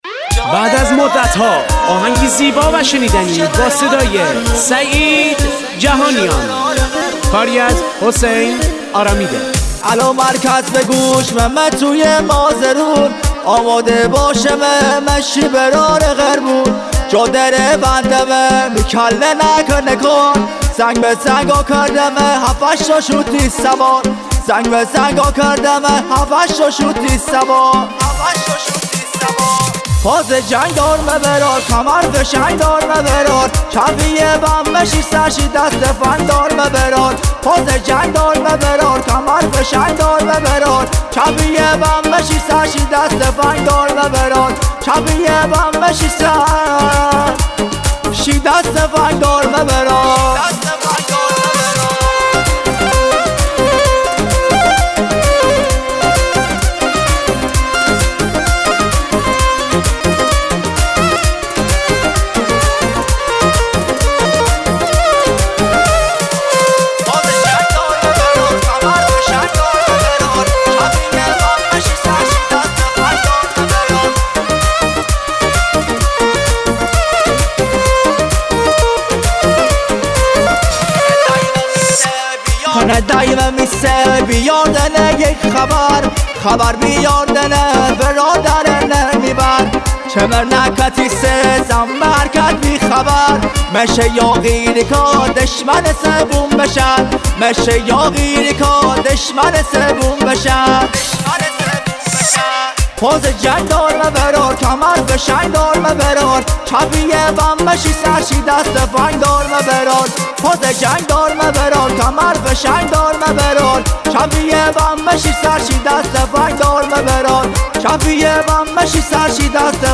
ریتمیک ( تکدست )